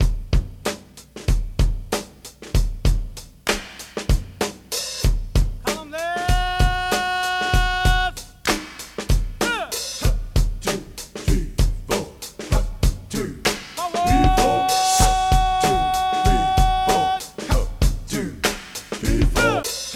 • 85 Bpm High Quality Breakbeat D# Key.wav
Free drum beat - kick tuned to the D# note. Loudest frequency: 1204Hz
85-bpm-high-quality-breakbeat-d-sharp-key-Qmy.wav